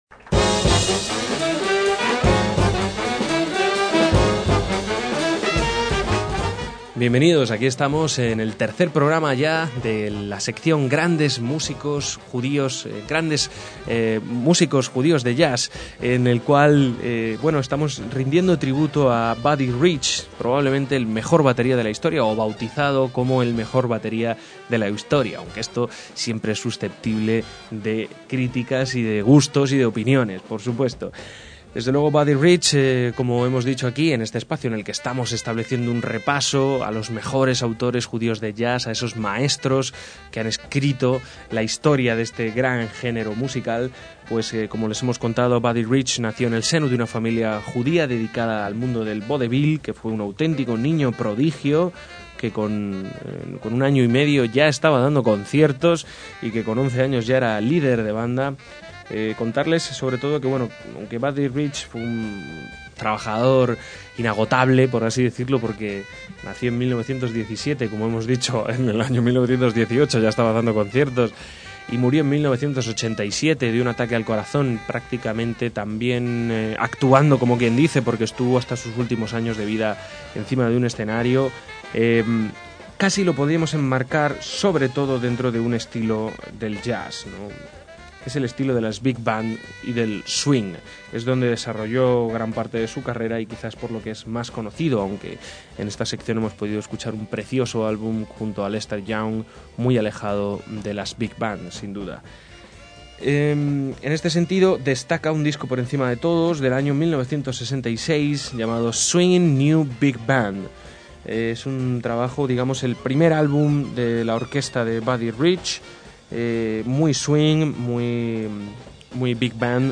baterista
Big Bands de estilo swing
grabada en directo